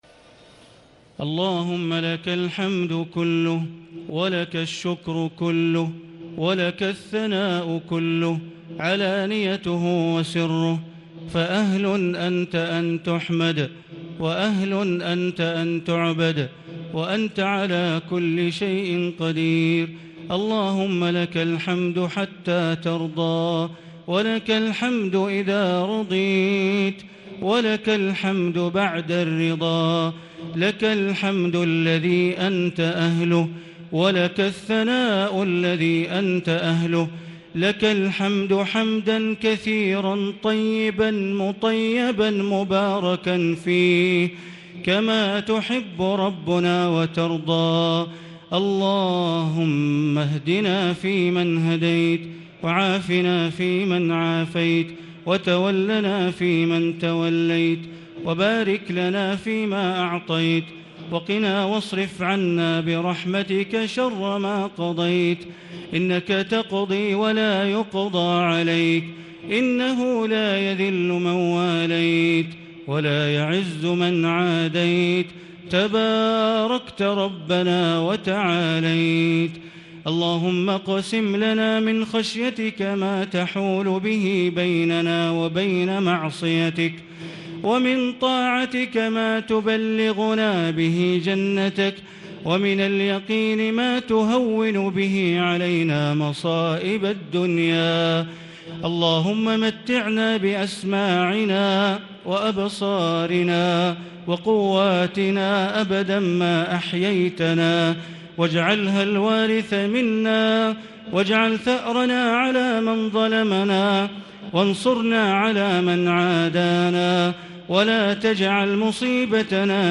دعاء القنوت ليلة 1 رمضان 1440هـ | Dua for the night of 1 Ramadan 1440H > تراويح الحرم المكي عام 1440 🕋 > التراويح - تلاوات الحرمين